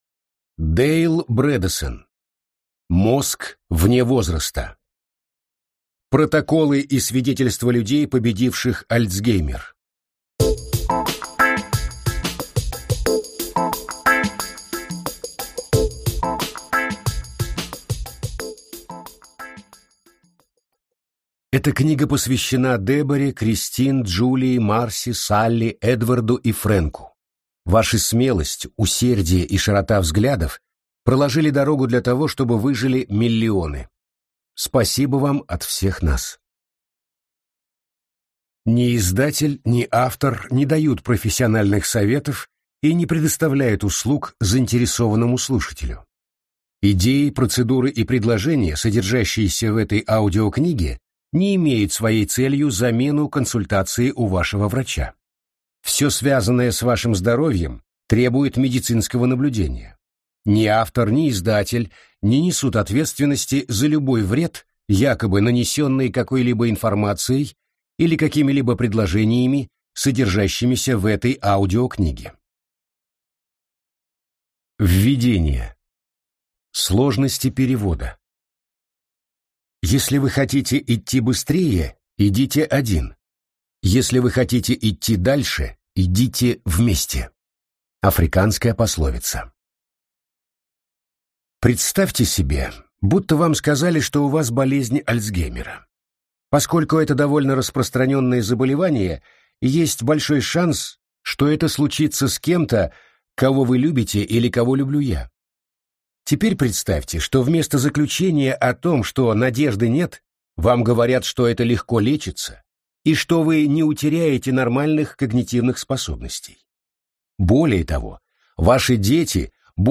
Аудиокнига Мозг вне возраста. Протоколы и свидетельства людей, победивших Альцгеймер | Библиотека аудиокниг